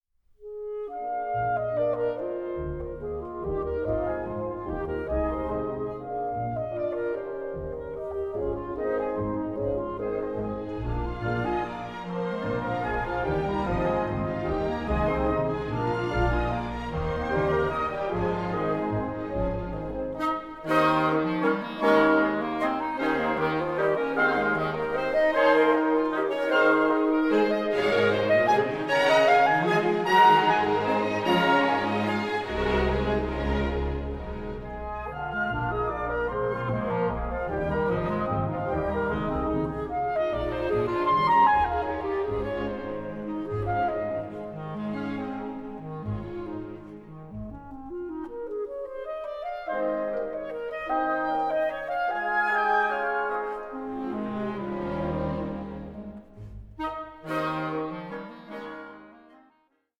Allegretto grazioso 3:53